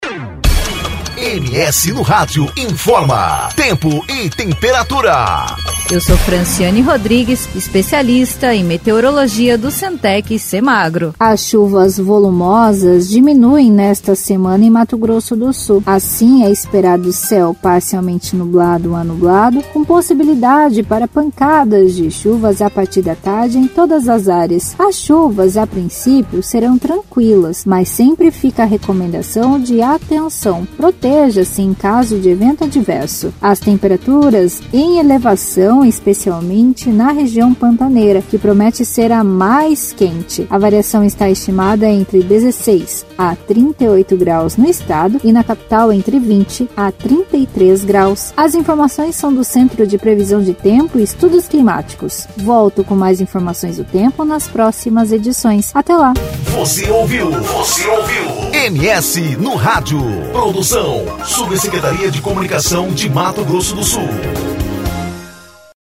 Previsão do Tempo: Semana com pancadas de chuvas e temperaturas em elevação